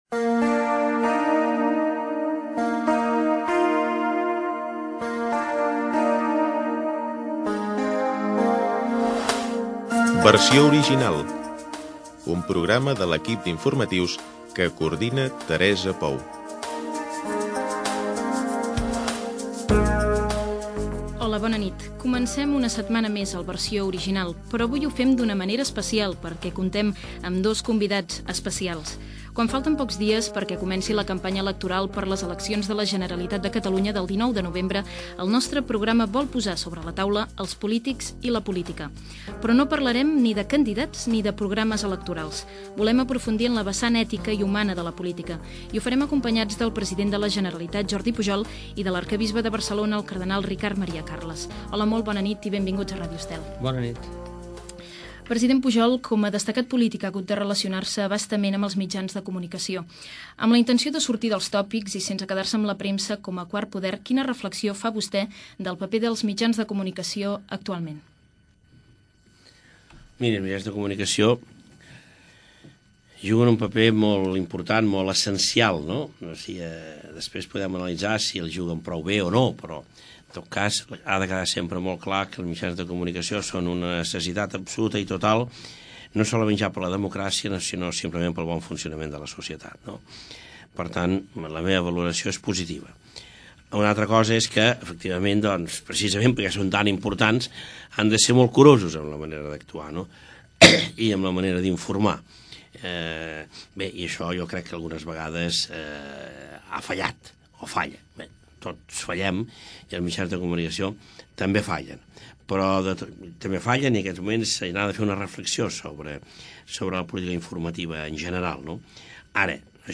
Careta musical del programa (veu d'Òscar Dalmau). Presentació del programa amb l'anunci dels convidats Jordi Pujol, president de la Generalitat de Catalunya i Ricard Maria Carles, arquebisbe de Barcelona i Cardenal. Inici de la entrevista focalitzada amb l'ètica i els mitjans de comunicació.
Informatiu